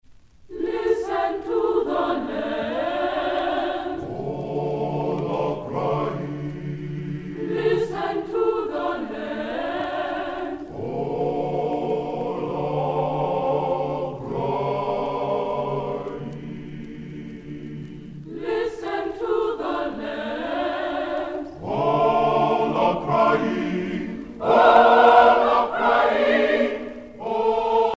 Spirituals
Pièce musicale éditée